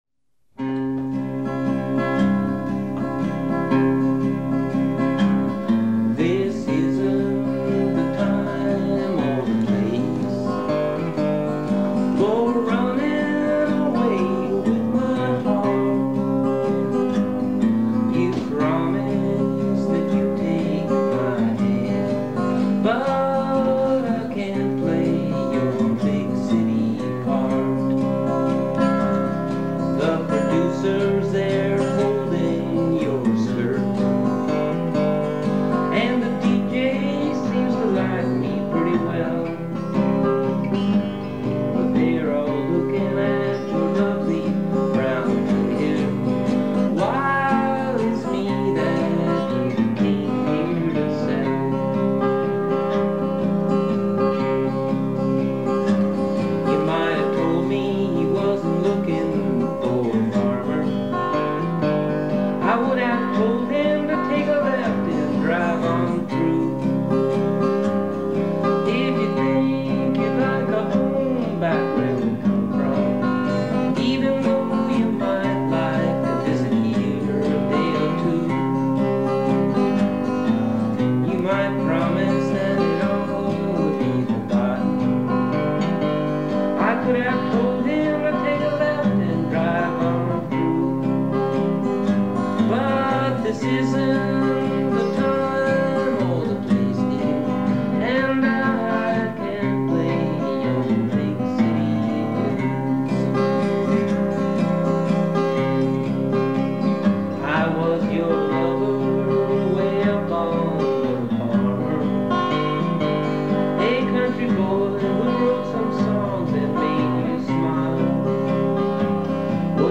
Big-City-Parts-Studio-Version.mp3